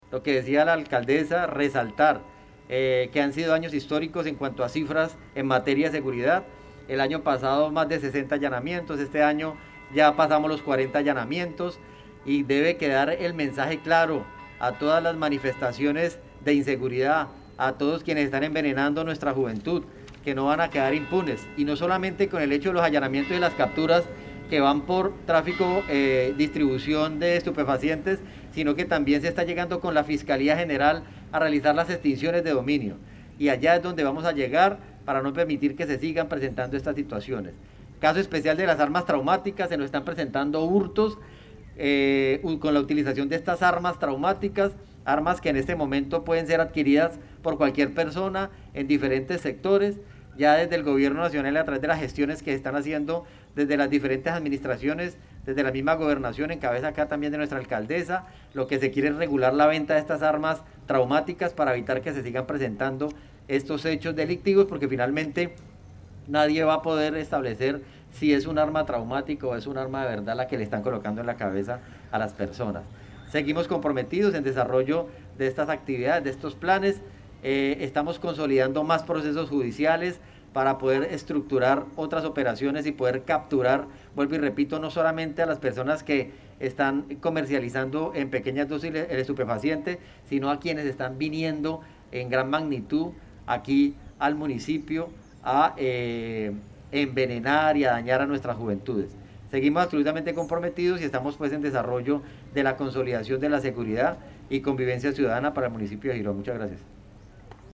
SECRETARIO DE SEGURIDAD JUAN CARLOS PINTO.mp3